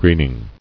[green·ing]